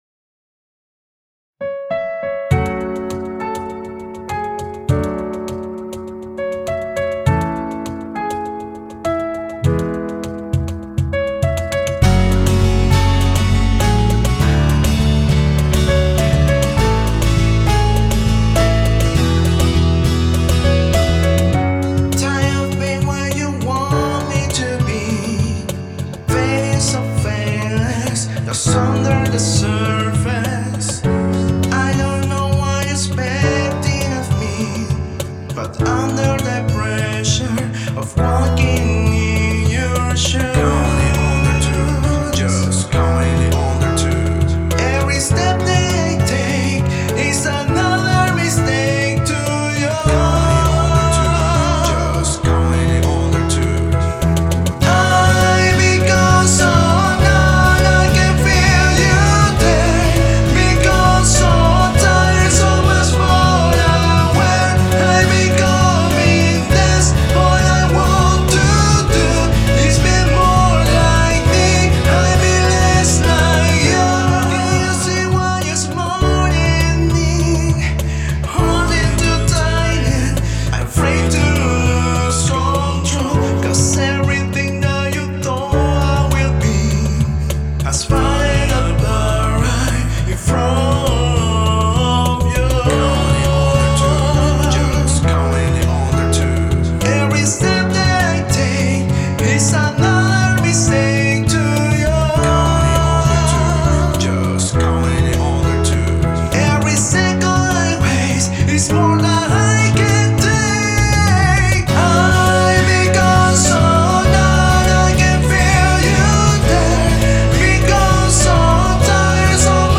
versión acústica